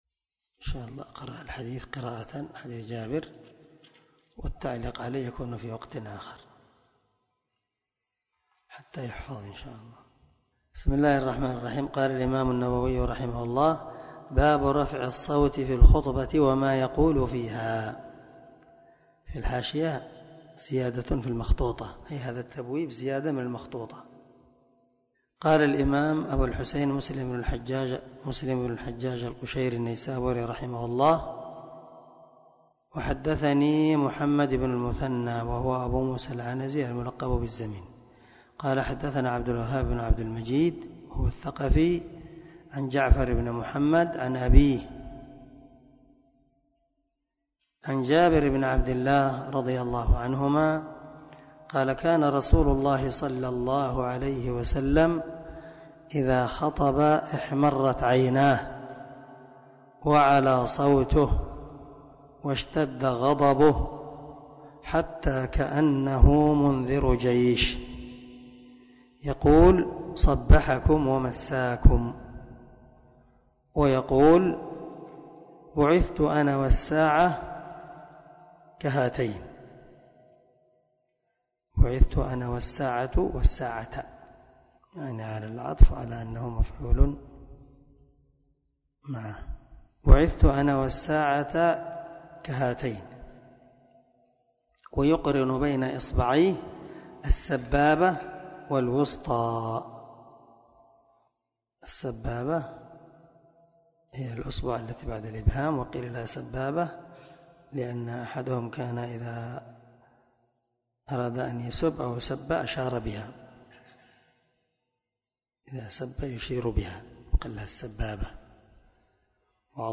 529الدرس 17 من شرح كتاب الجمعة الحديث رقم (867) من صحيح مسلم